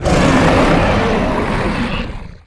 horror
Dragon Death Roar 7